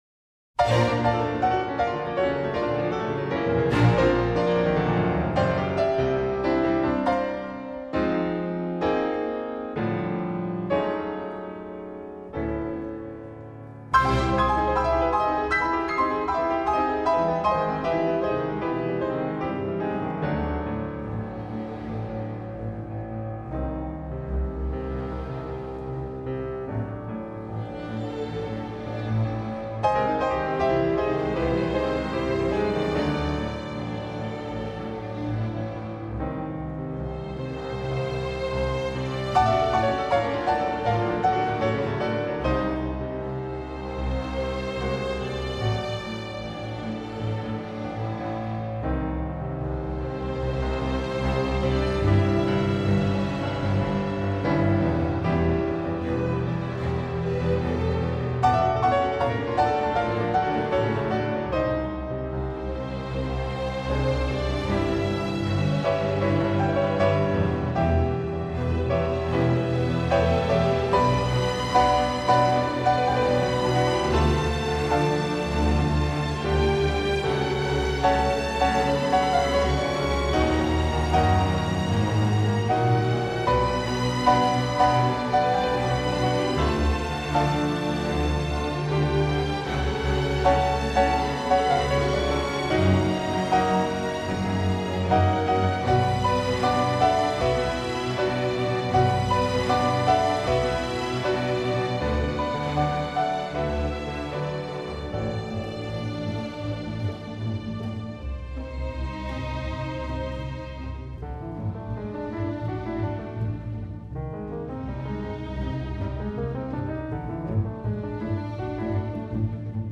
ferrero-capriccio-per-pf-e-archi.mp3